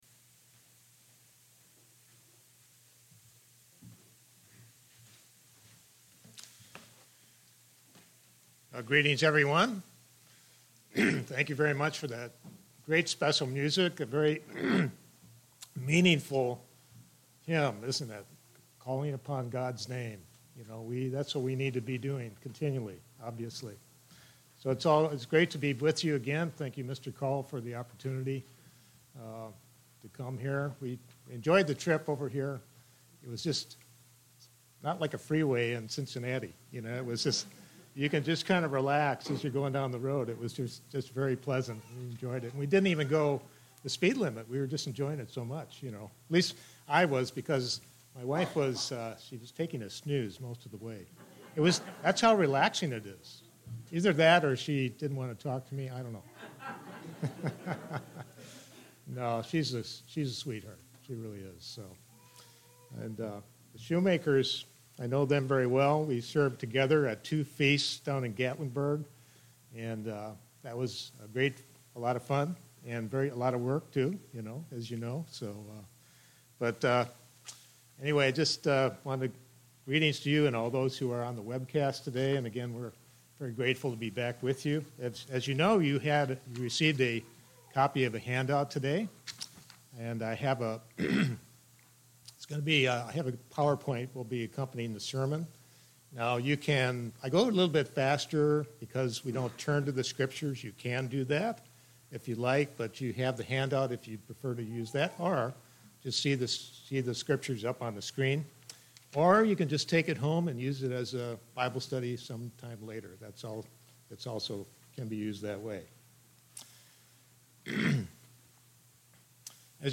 Sermons
Given in Portsmouth, OH Paintsville, KY